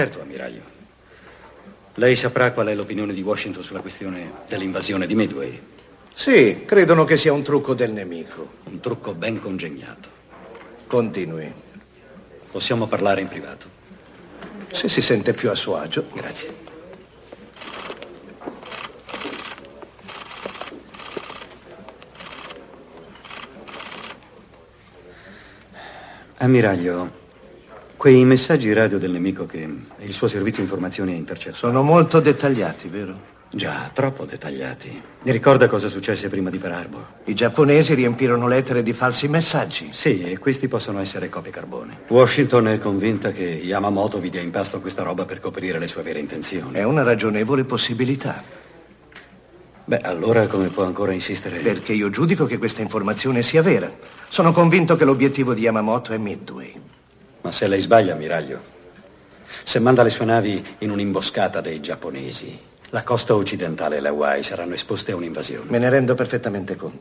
voce di Luigi Vannucchi nel film "La battaglia delle Midway", in cui doppia J
Una recitazione inquieta e nervosa, intrisa di un'intensità drammatica e dolorosa, caratterizzava le sua interpretazioni teatrali e televisive.